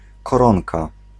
Ääntäminen
IPA: /kɑnt/